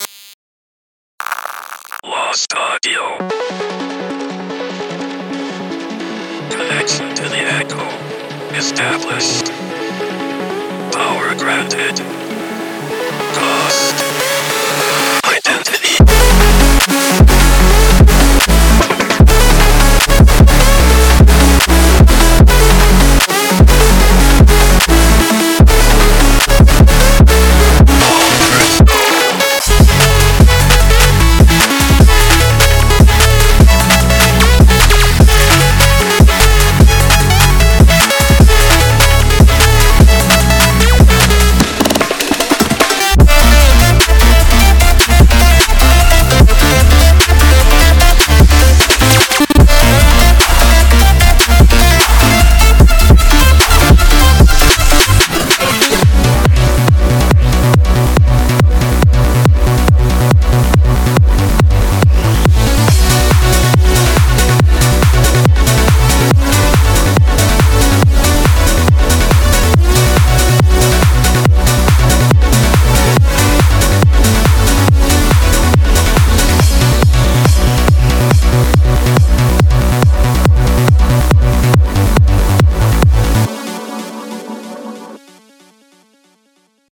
Genre:Trance
このパックは150 BPMを中心に構成されており、トランス、ハードウェーブ、そしてオルタードソウルの制作に最適です。
デモサウンドはコチラ↓
143 Melodic Elements (arps, leads, plucks, pads)
77 FX Elements (lifters, zaps, atmospheres)